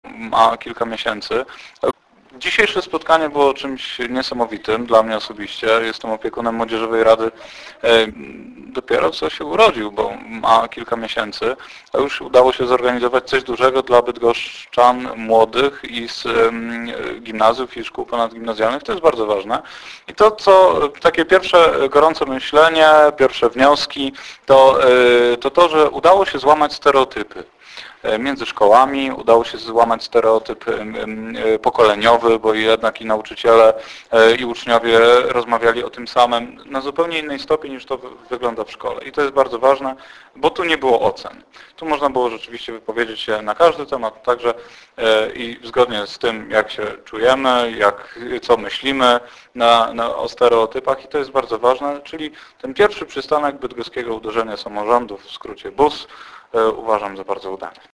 BUS I - wywiady